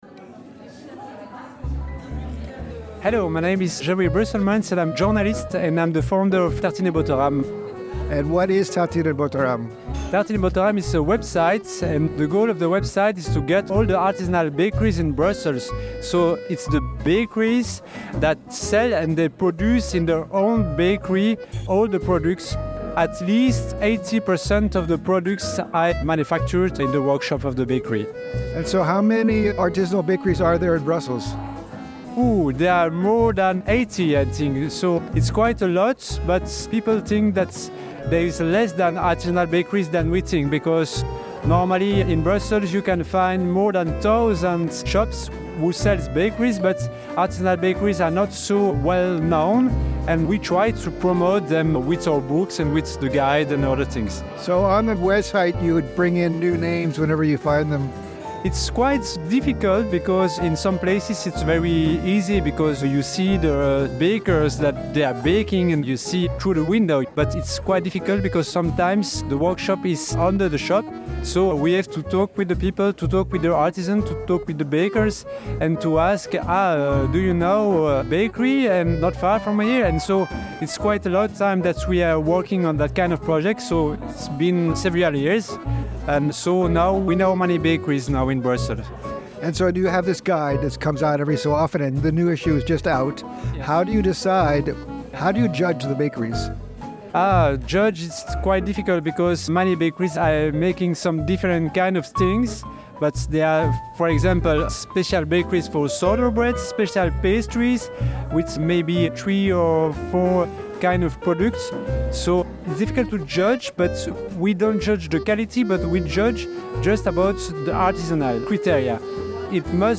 at the launch party for this year's edition